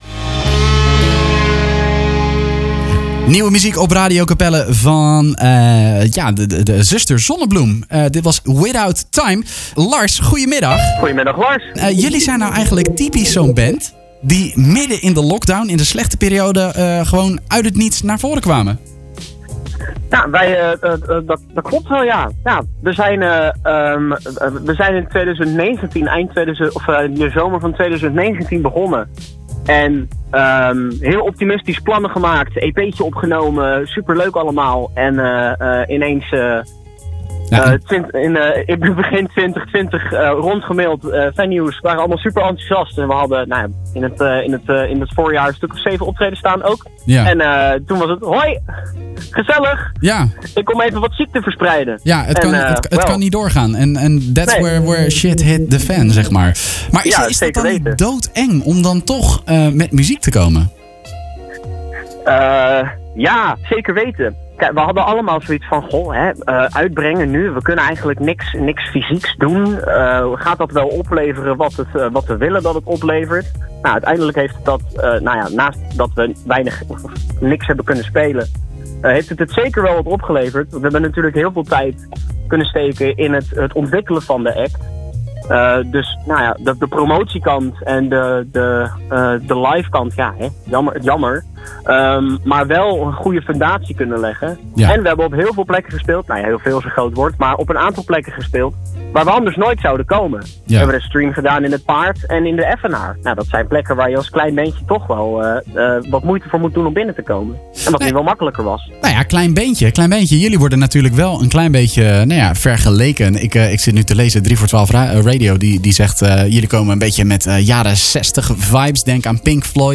Deze psychedelische rockband is gevormd vanuit de Herman Brood Academie en laat zich inspireren door muziek uit de jaren '60. Zuster Zonnebloem betovert het Bamboebos met groovy ritmes, kleurrijke melodie�n en hypnotiserende, rauwe zang.